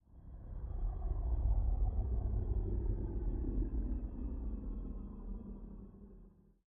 Minecraft Version Minecraft Version snapshot Latest Release | Latest Snapshot snapshot / assets / minecraft / sounds / ambient / nether / nether_wastes / dark2.ogg Compare With Compare With Latest Release | Latest Snapshot